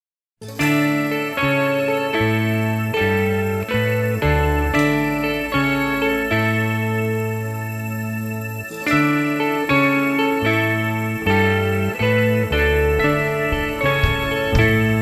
Sound-Alikes